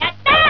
Vox
ChunLi-'YaTa'.wav